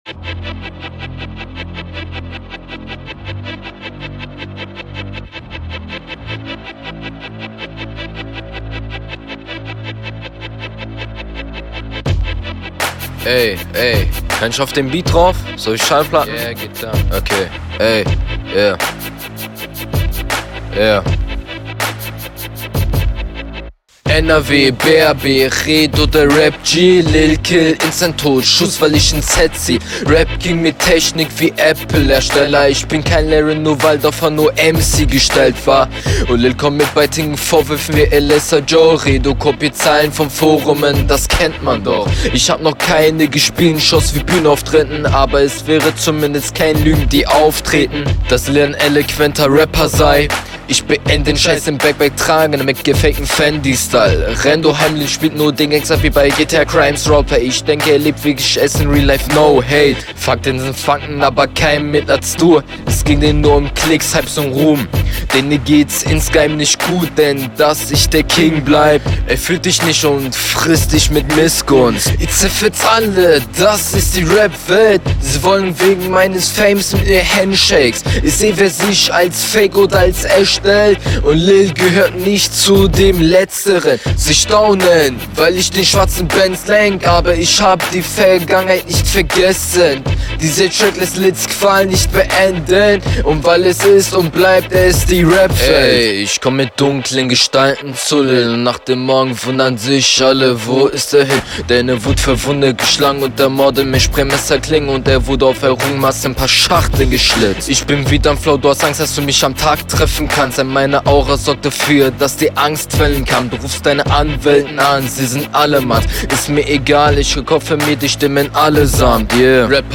Ist halt mehr ein Track als eine Battlerunde.